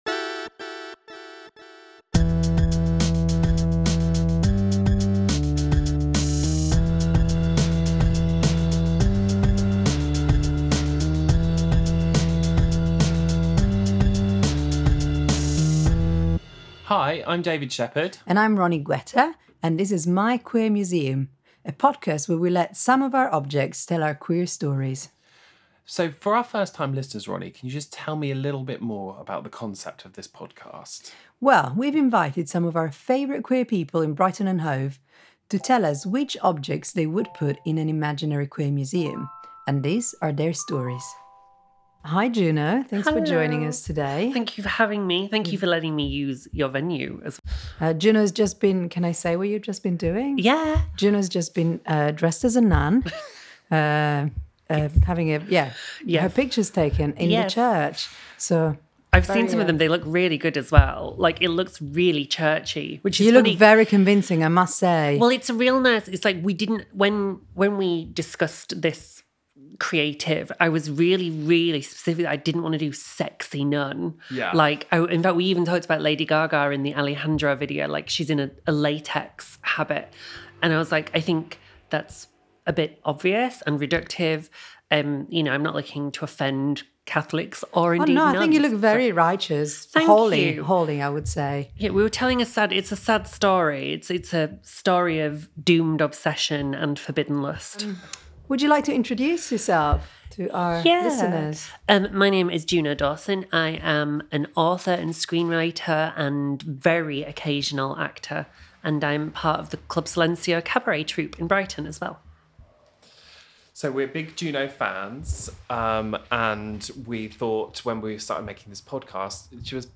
In this interview Juno talks about teenage crushes, growing up as a queer person, being a writer, gender and fashion. Recorded on November 3, 2020, at The Spire, Brighton, as part of the My Queer Museum podcast series.